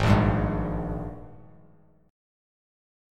G#M7sus2 chord